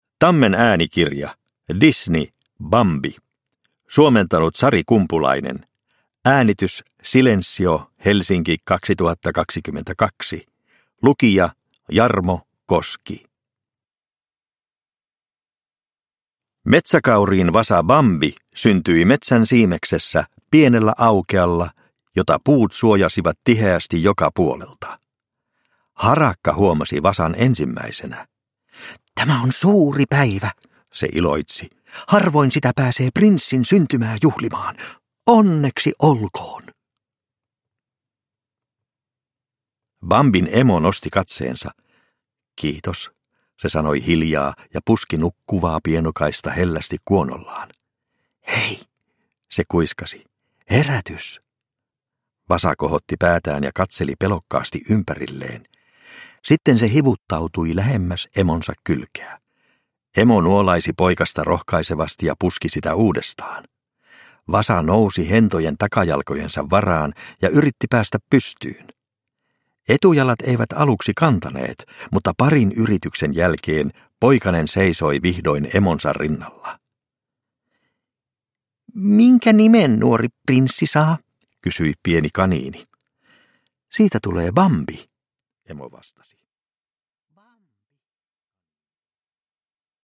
Bambi – Ljudbok – Laddas ner